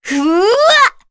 Seria-Vox_Casting1.wav